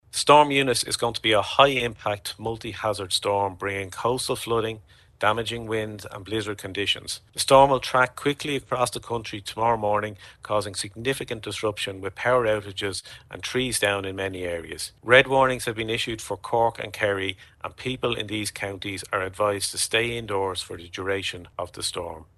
Head of the National Directorate for Emergency Management is Keith Leonard: